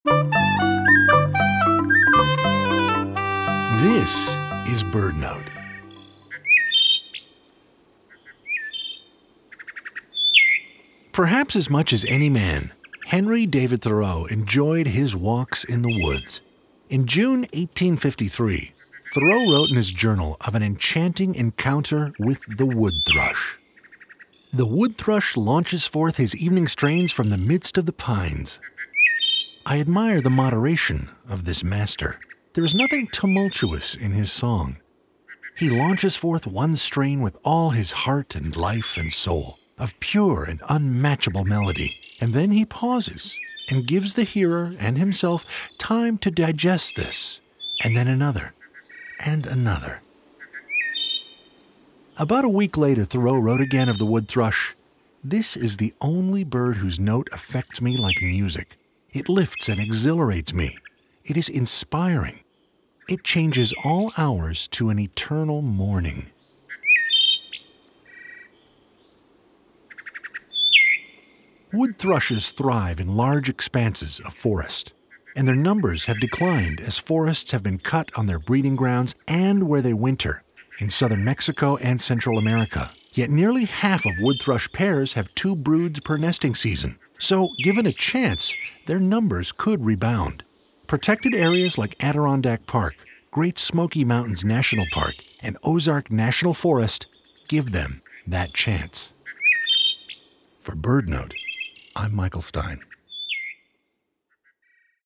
BirdNote is sponsored locally by Chirp Nature Center and airs live everyday at 4 p.m. on KBHR 93.3 FM.